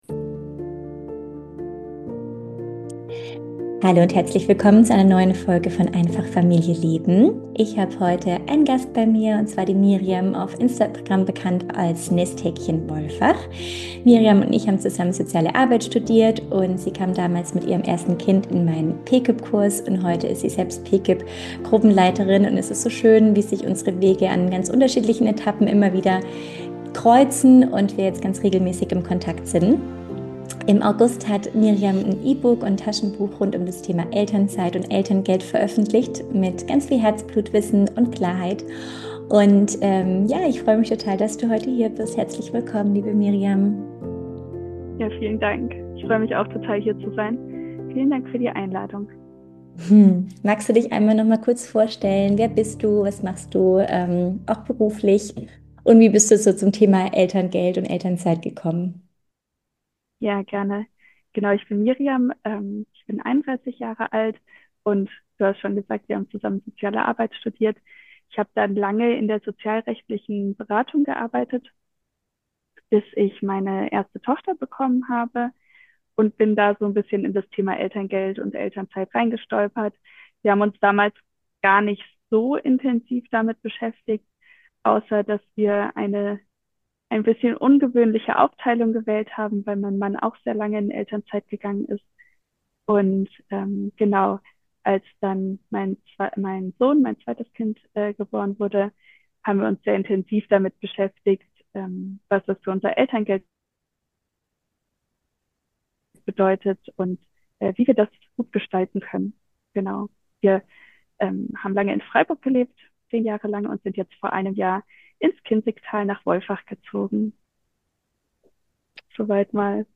Wir sprechen über gängige Mythen, emotionale Entscheidungen, gesellschaftliche Erwartungen – und darüber, warum echte Augenhöhe nur entstehen kann, wenn beide Elternteile Care-Arbeit wirklich erleben. Ein ehrliches, informatives und stärkendes Gespräch für alle, die Elternzeit bewusst gestalten wollen.